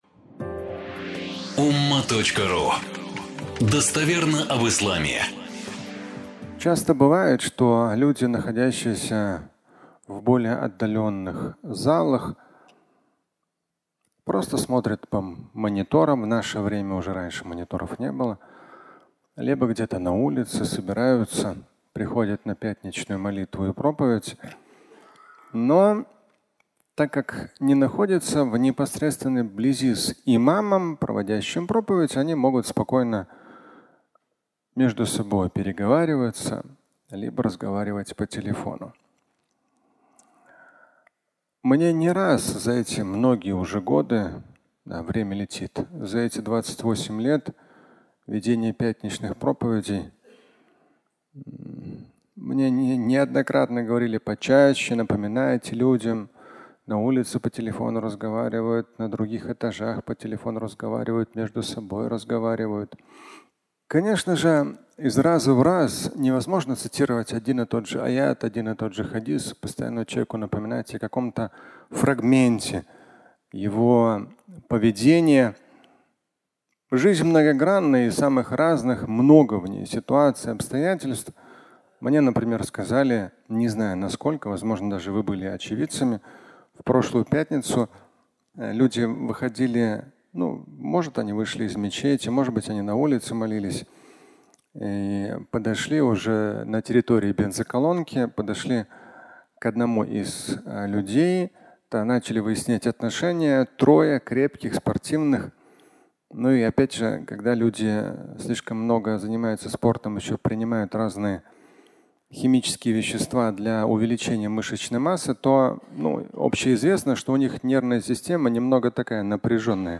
Фрагмент пятничной проповеди